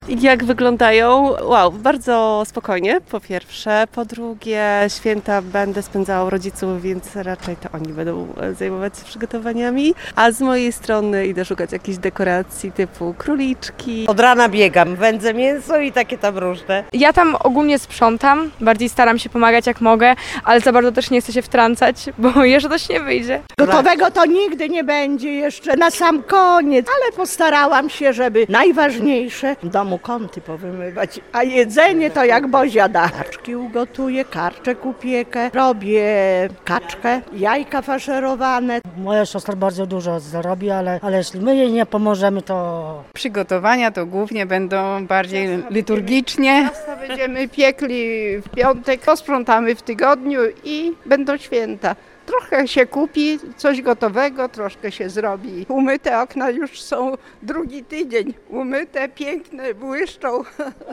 Zapytaliśmy ełczan, jak idą im przygotowania do świąt.